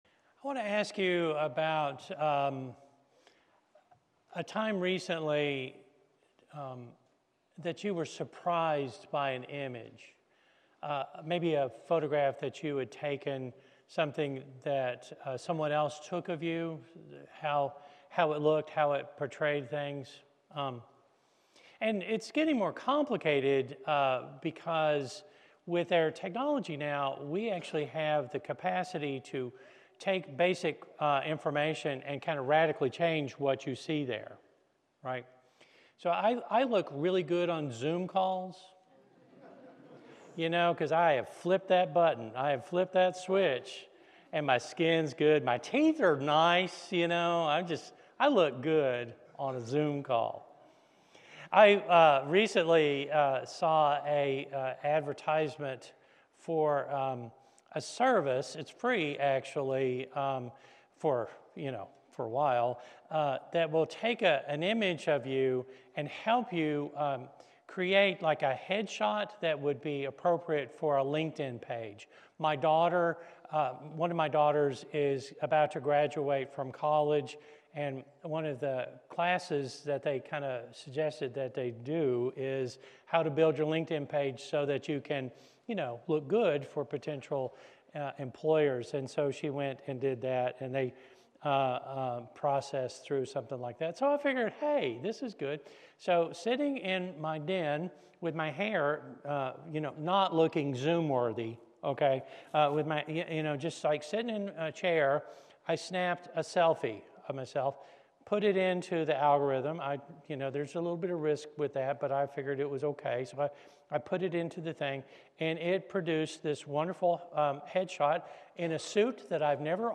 The sermon redefines hope not as a filter that blurs reality, but as the decision to keep asking hard questions, to look for small signs of God's reign, and to participate in concrete actions aligned with God's promised future—even when our hope trembles.